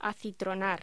Locución: Acitronar